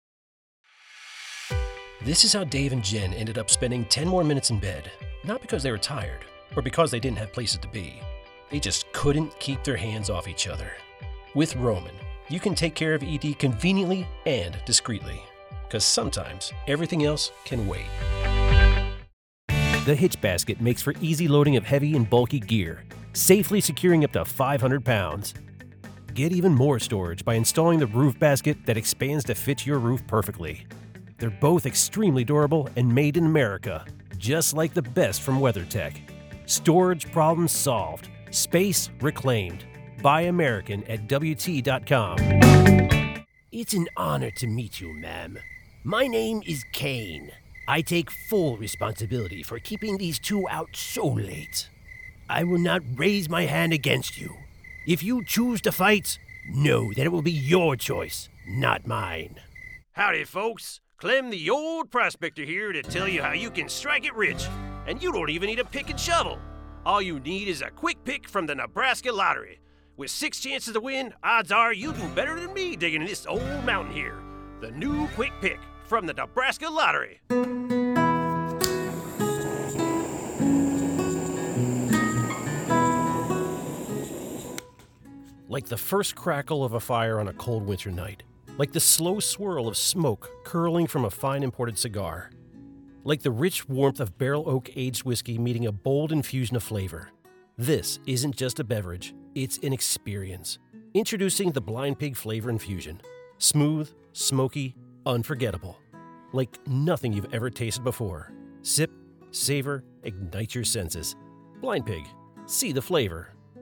Demos
• Microphone: Professional large-diaphragm condenser microphone
• Environment: Fully treated, certified-professional home studio for noise-free, clean narration